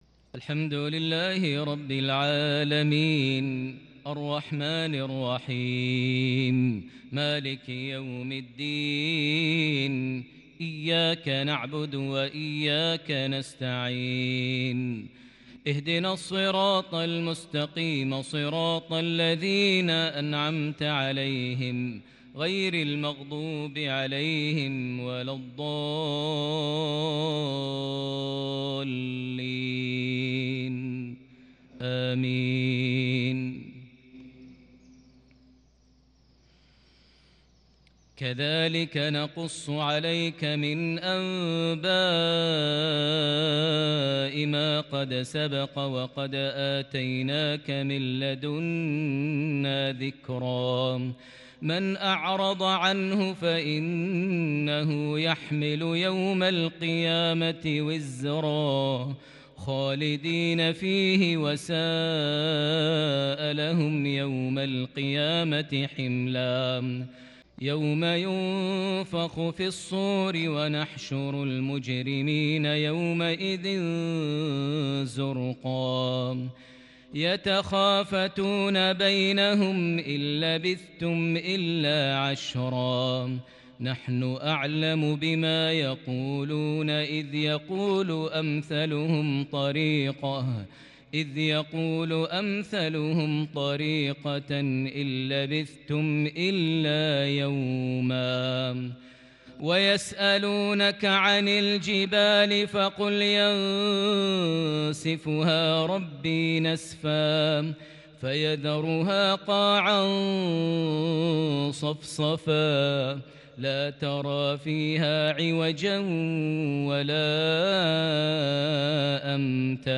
مغربية مباركة من سورة طه (99-114) | 18 رجب 1442هـ > 1442 هـ > الفروض - تلاوات ماهر المعيقلي